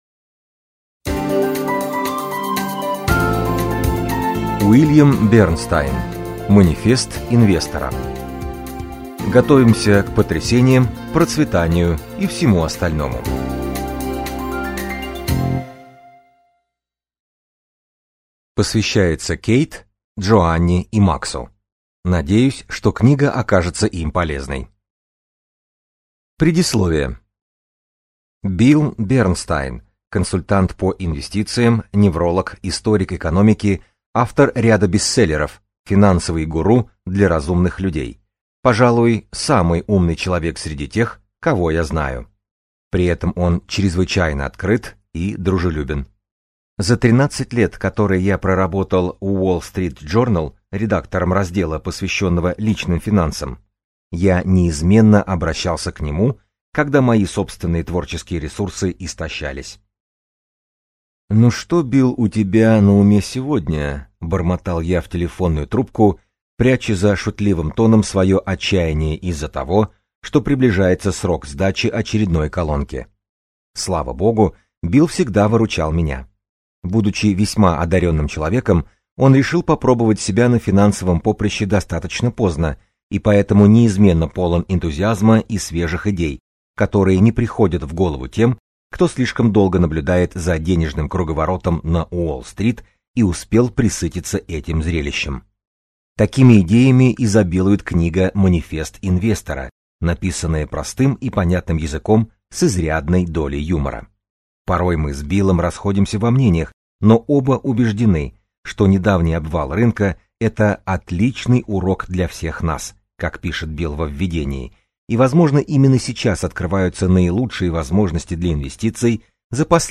Аудиокнига Манифест инвестора: Готовимся к потрясениям, процветанию и всему остальному | Библиотека аудиокниг